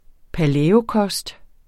Udtale [ paˈlεːoˌkʌsd ]